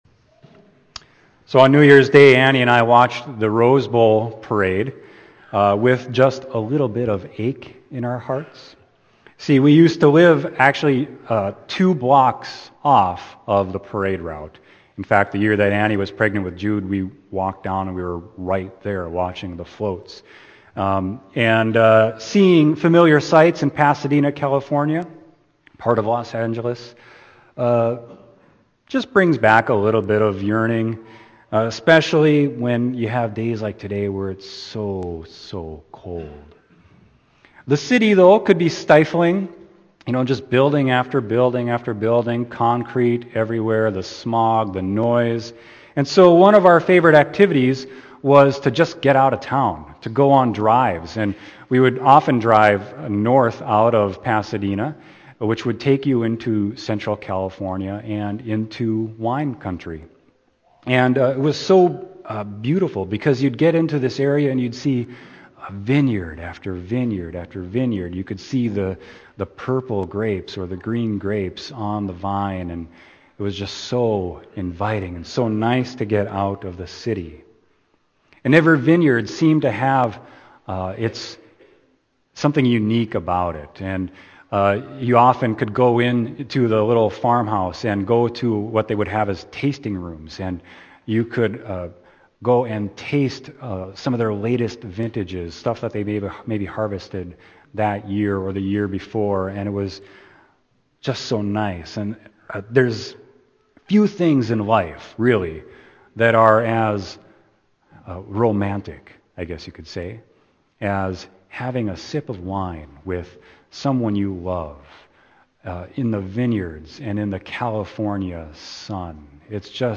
Sermon: John 2.1-11